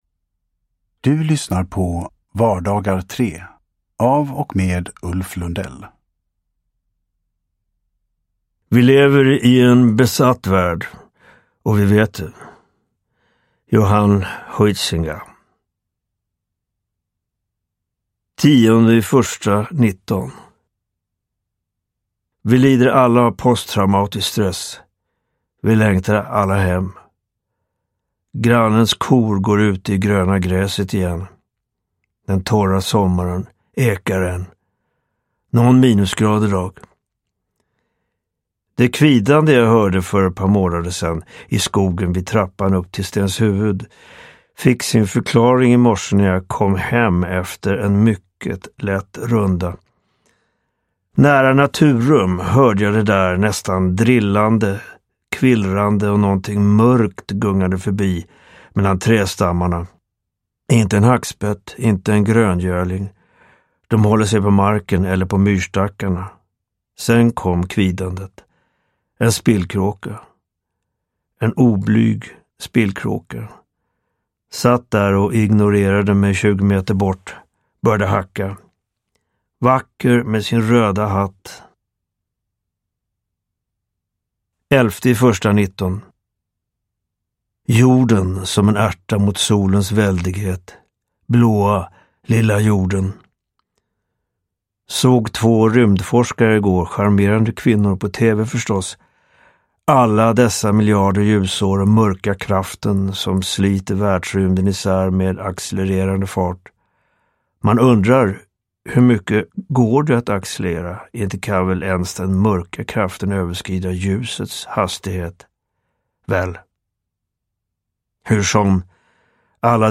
Vardagar 3 – Ljudbok – Laddas ner